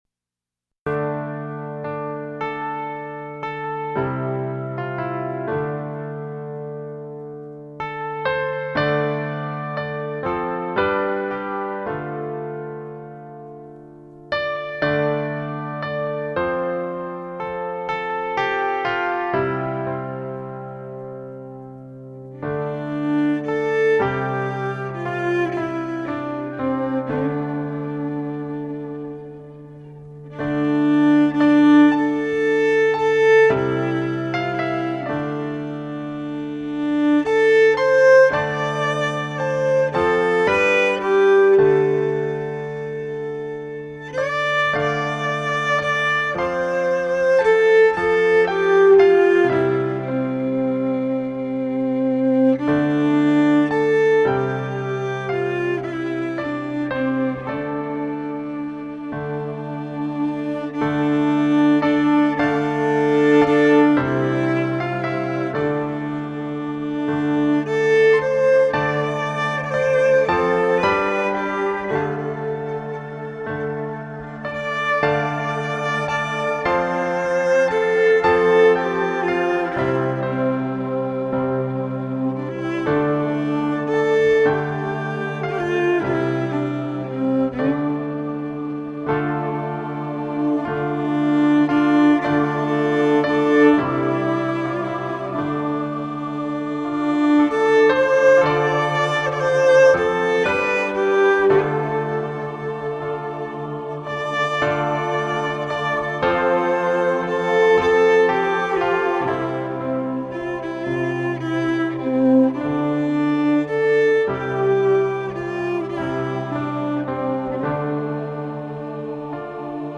Fiddle Lullabys
Keyboard